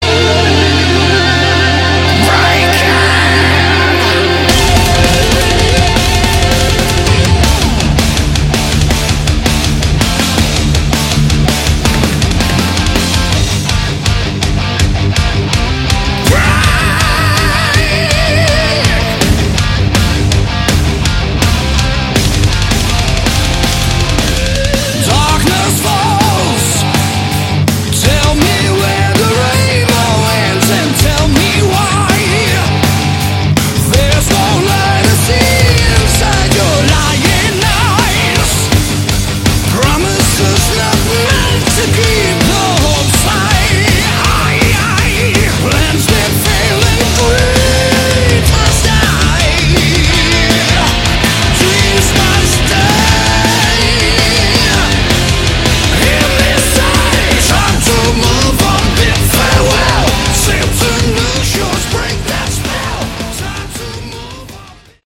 Category: Melodic Metal
guitars
vocals
bass
drums